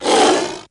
FX [Roar].wav